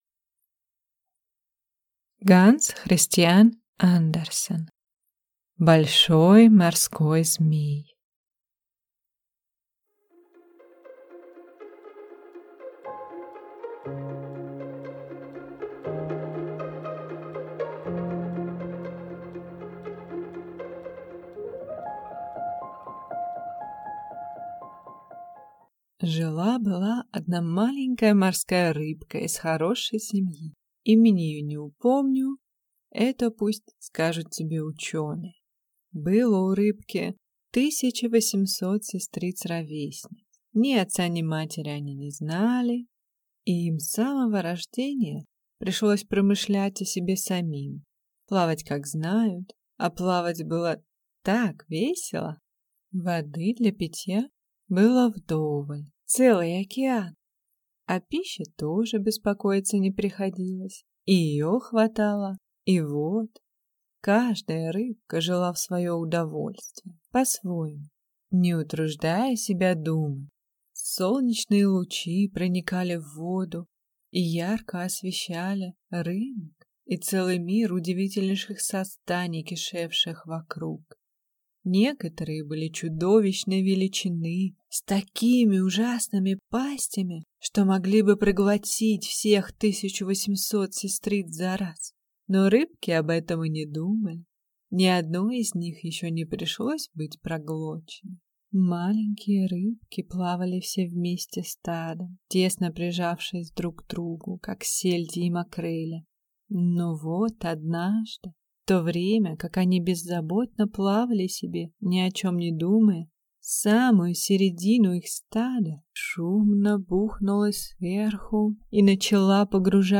Аудиокнига Большой морской змей | Библиотека аудиокниг